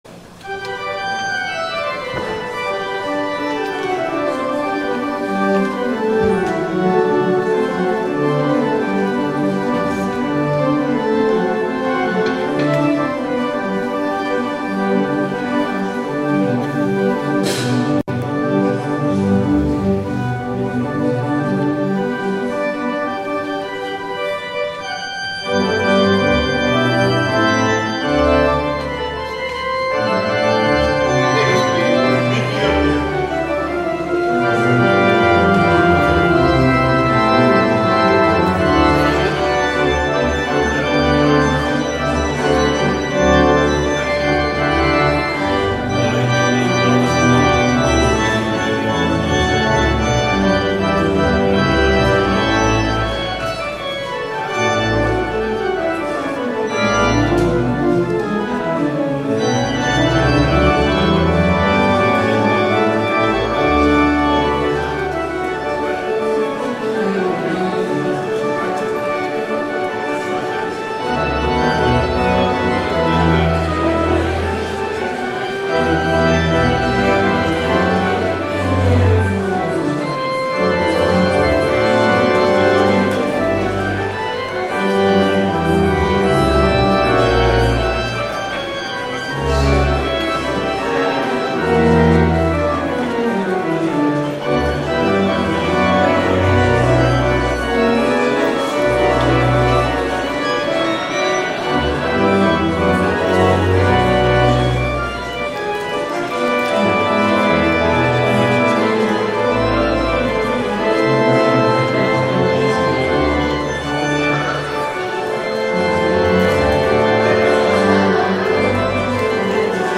THE PRELUDE
PreludeInGMajor.mp3